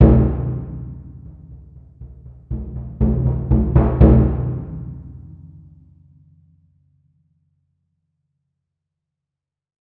その響きを再現するために、TAIKO THUNDERはスタジオではなくホールでサンプリング収録を行いました。
• Rear：太鼓の背面側の皮のダイレクトマイクのサウンド（モノラル）です。